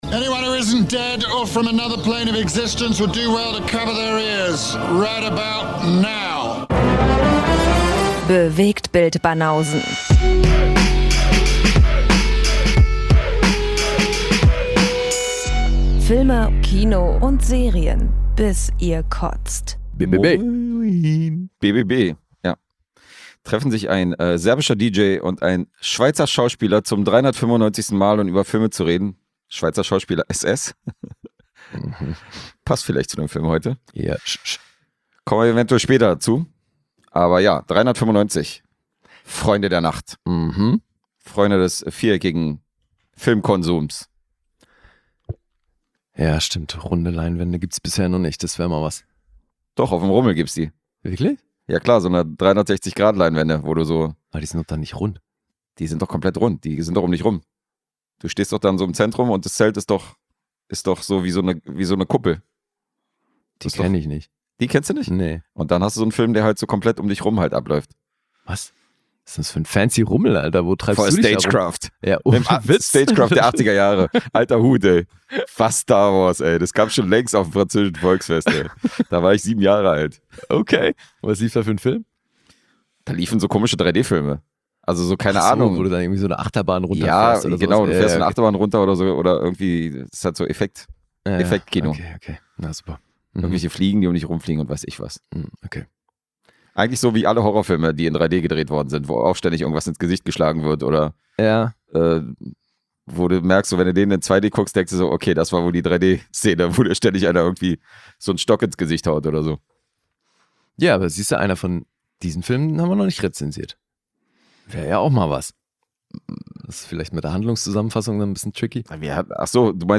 Zwei Dudes - manchmal mit Gästen - quatschen über Gesehenes aus Kino, Homekino und Streaming-Plattformen und punkten zudem mit gefährlichem Halbwissen.